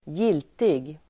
Uttal: [²j'il:tig]